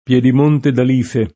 pLHdim1nte d al&fe] (Camp.); Piedimonte Etneo [